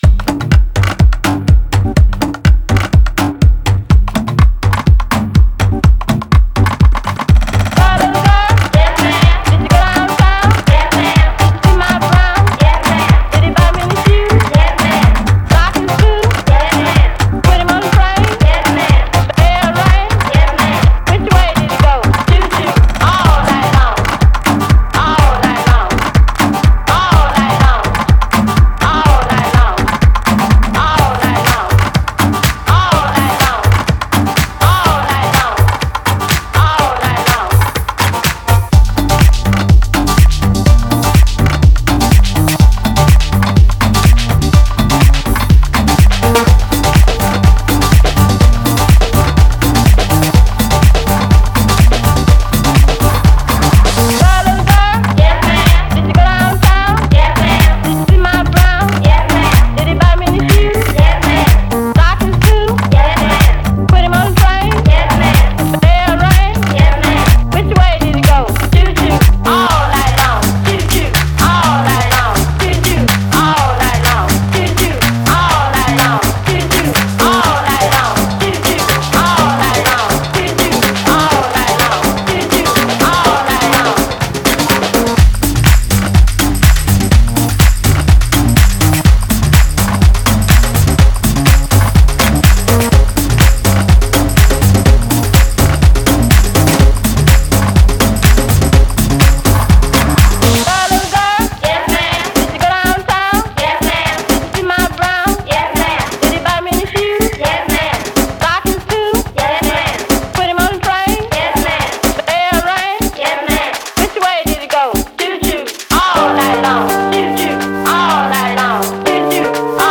^^ I guess it’s the mistress voice.